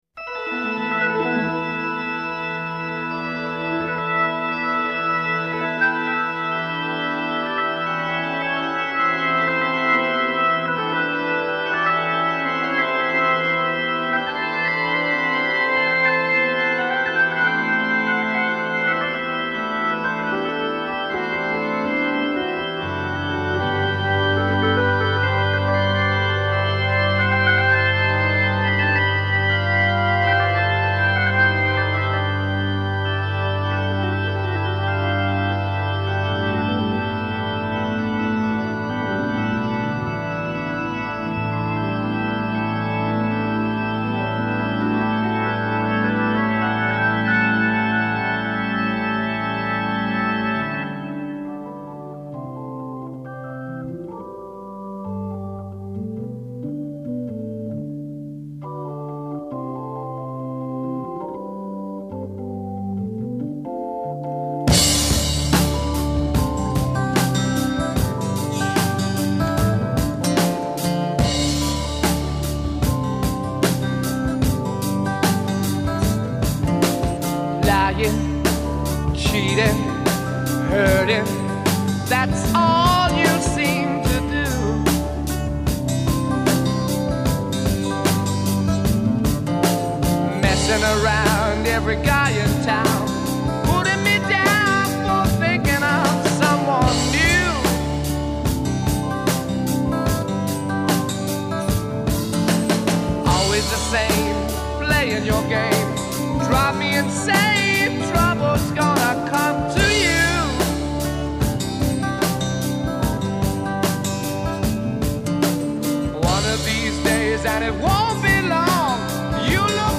Recorded in October 1968 in Olympic Studios (Barnes).
Intro 1 Pipe organ introduction
Verse 8 + 8 Solo vocal over backing track. a
Fill 4 Acoustic guitar fill.
Add electric guitar fill. d